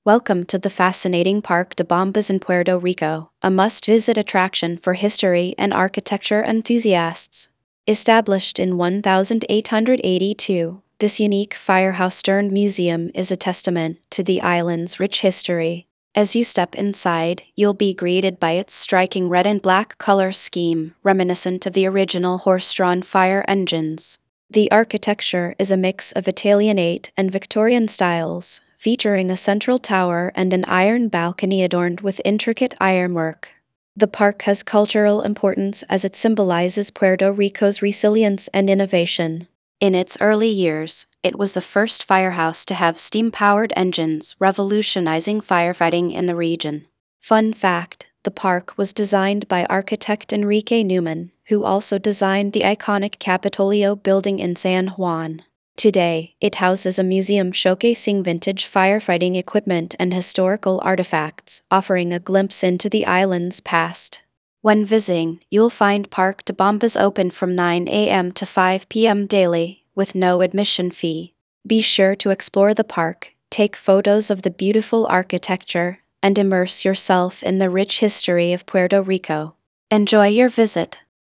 karibeo_api / tts / cache / 3aab9bee26ea9a0f9442b56a4345c782.wav